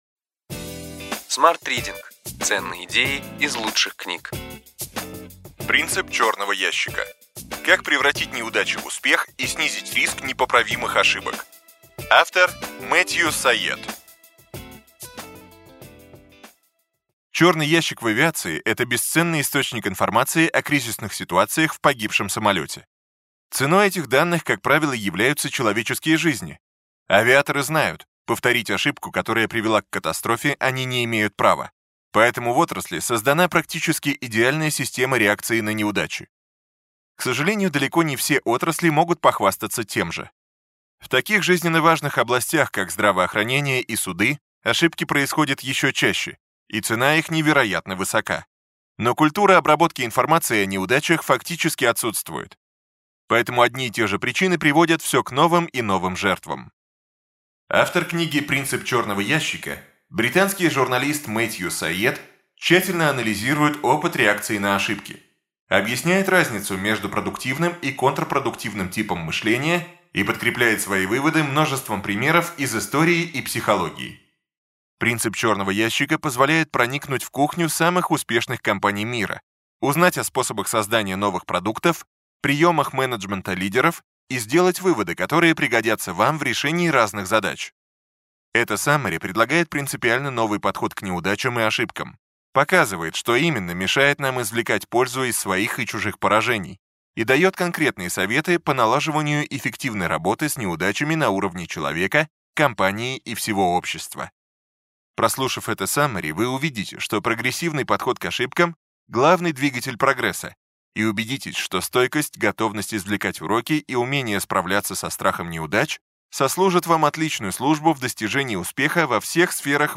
Аудиокнига Ключевые идеи книги: Принцип «черного ящика». Как снизить риск неудач и непоправимых ошибок.